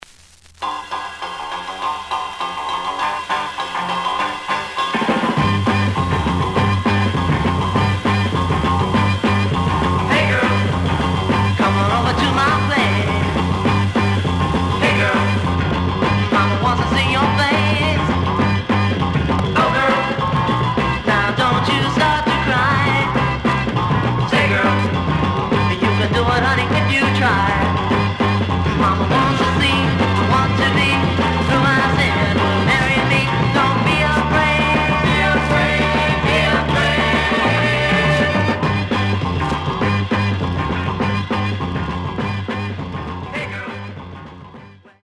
The audio sample is recorded from the actual item.
●Genre: Rock / Pop
Some noise on B side.